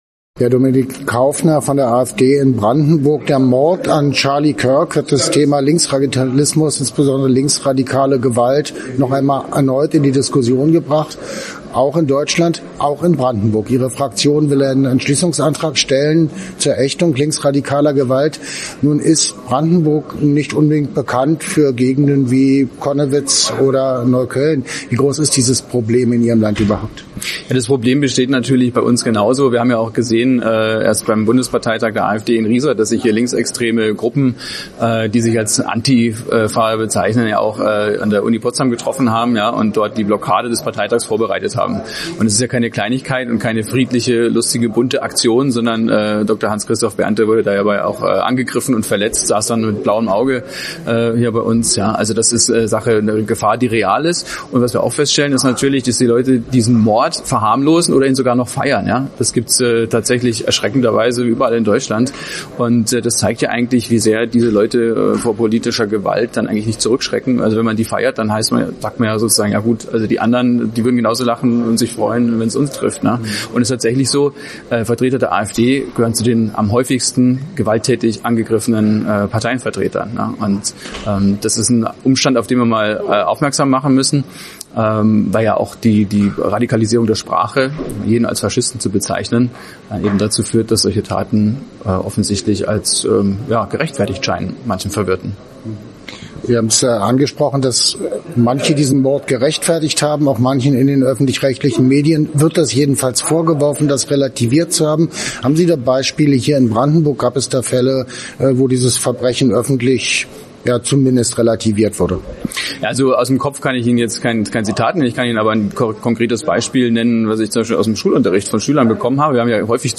mit dem Landtagsabgeordneten Dominik Kaufner.